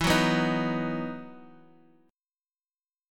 EM7sus2 chord